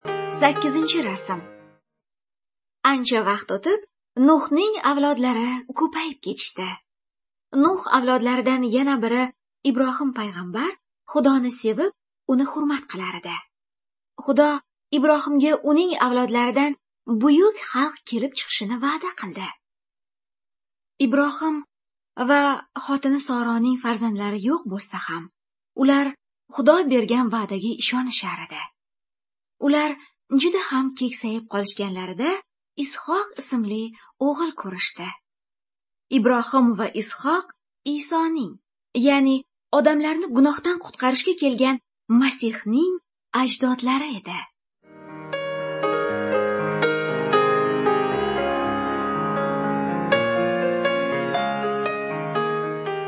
Good News (Female)